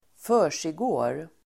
Ladda ner uttalet
försiggå verb, be going on, take place Grammatikkommentar: x & Uttal: [²f'ö:r_sigå:r] Böjningar: försiggick, försiggått, försiggå, försiggår Synonymer: pågå Definition: äga rum, hända, ske Exempel: vad försiggår här?